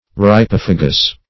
Meaning of rypophagous. rypophagous synonyms, pronunciation, spelling and more from Free Dictionary.
Search Result for " rypophagous" : The Collaborative International Dictionary of English v.0.48: Rypophagous \Ry*poph"a*gous\ (r[-i]*p[o^]f"[.a]*g[u^]s), a. [Gr.